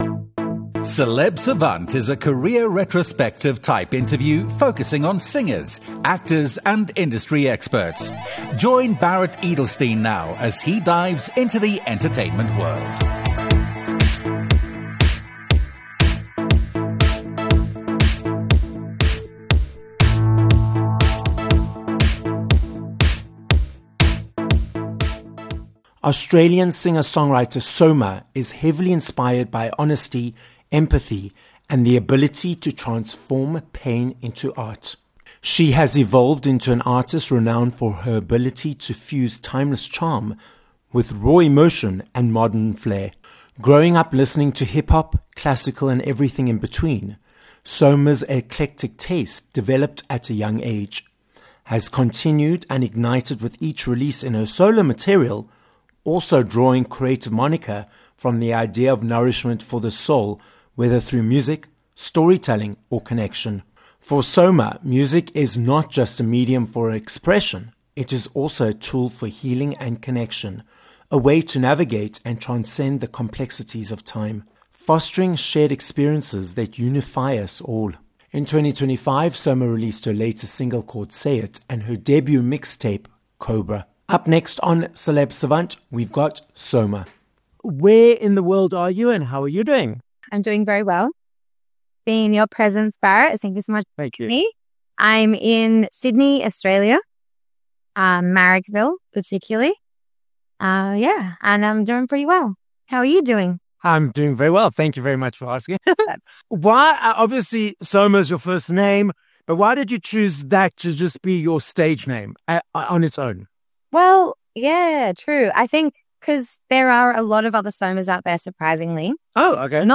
an Australian singer and songwriter joins us on this episode of Celeb Savant.